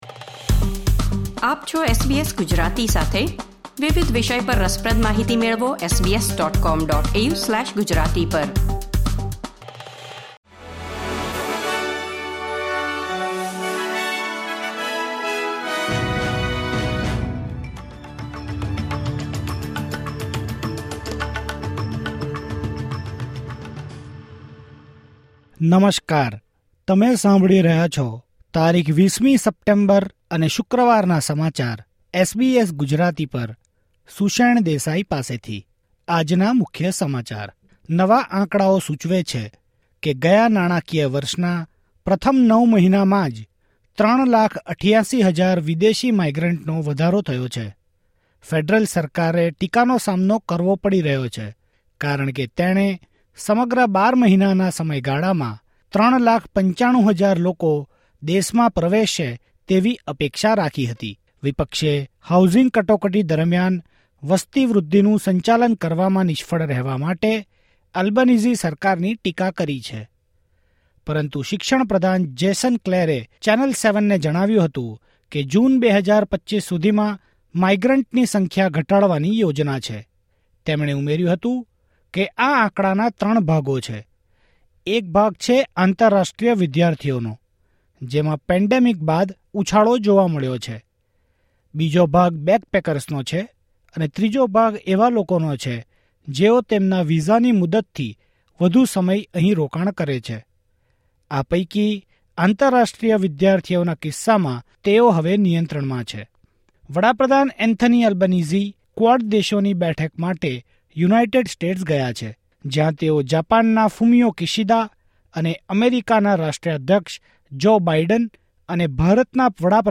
૨୦ સપ્ટેમ્બર ૨୦૨૪ના મુખ્ય સમાચાર